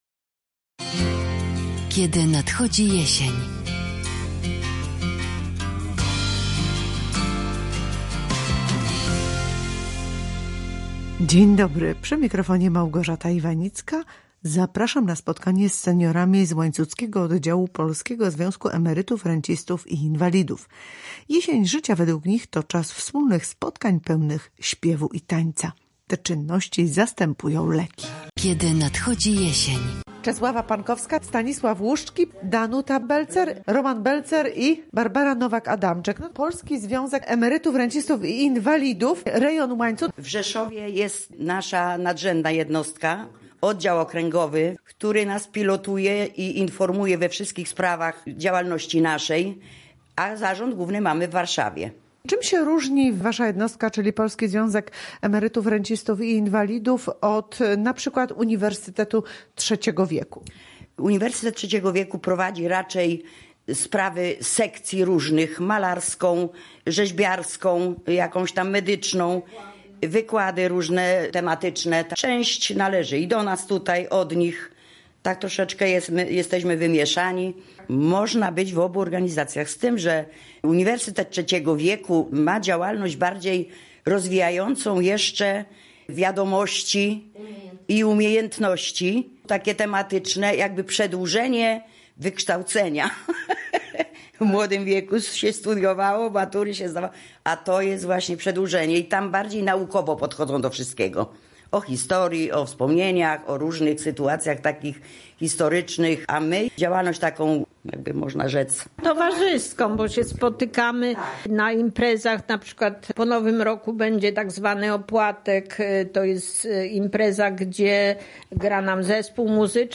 Rozmowy z łańcuckimi seniorami